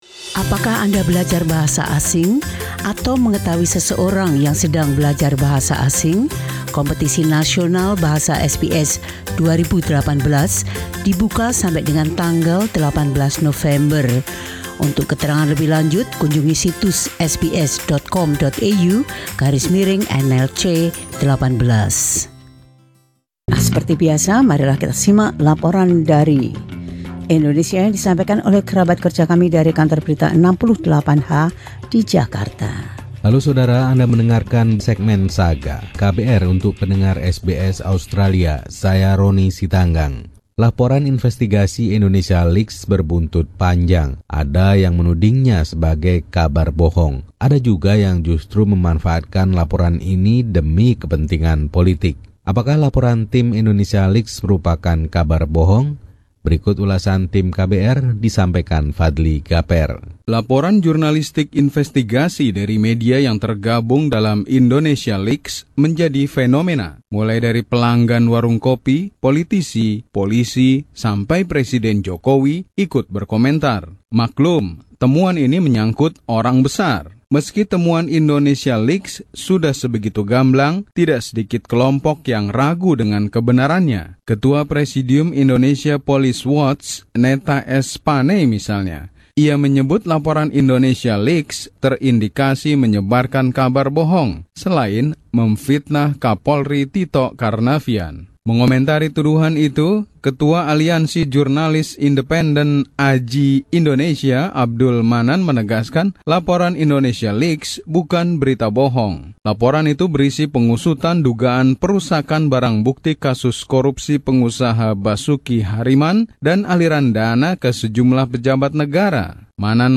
Laporan khusus KBR 68H ini menyelidiki integritas IndonesiaLeaks serta para pengkritiknya.